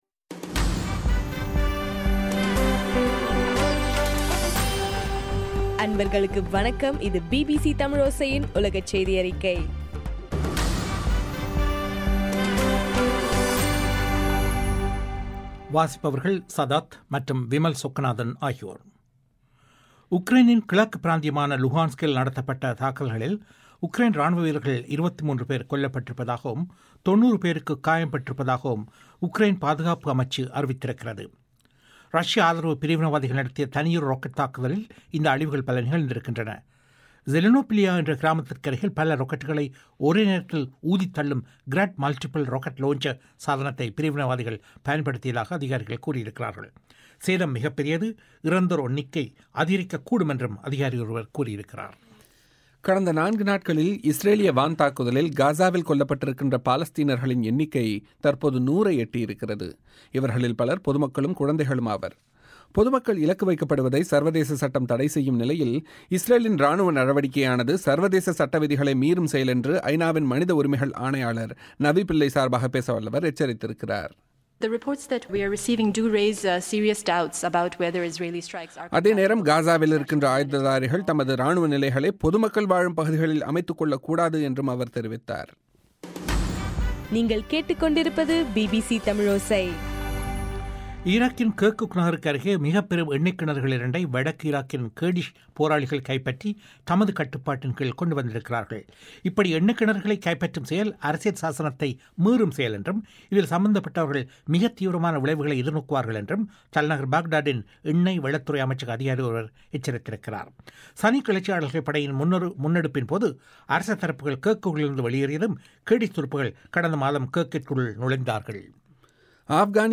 ஜூலை 11 2014 பிபிசி தமிழோசையின் உலகச் செய்திகள்